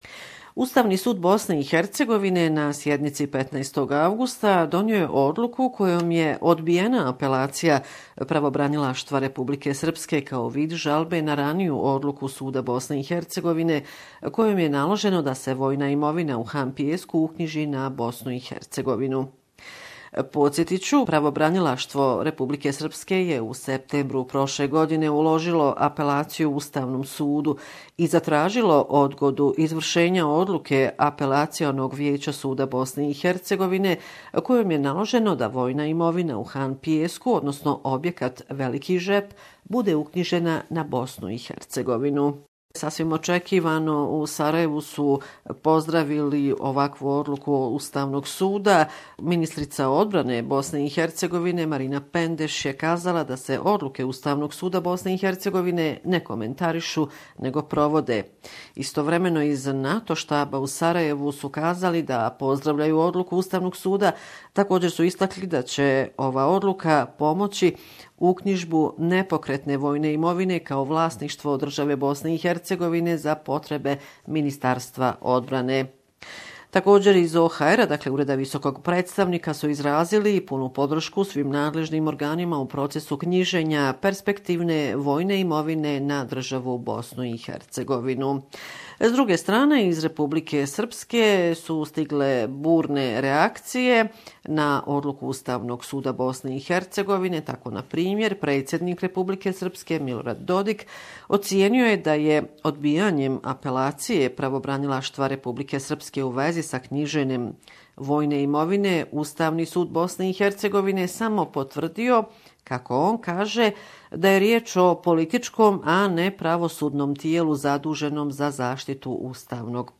Report from Bosnia and Herzegovina Sarajevo Film Festival